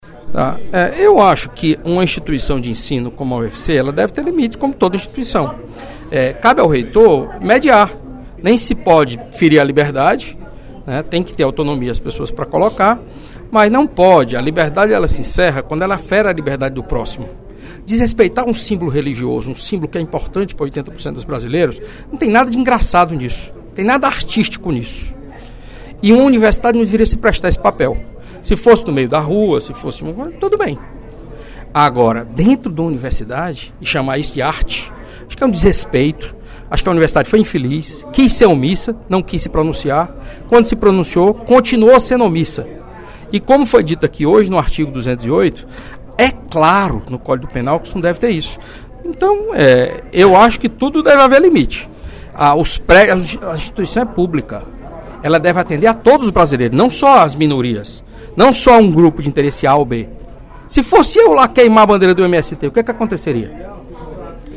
O deputado Carlos Matos (PSDB) também criticou, no primeiro expediente da sessão plenária desta terça-feira (31/05), o monólogo "Histórias Compartilhadas", apresentado durante o seminário sobre gênero e sexualidade da Universidade Federal do Ceará (UFC).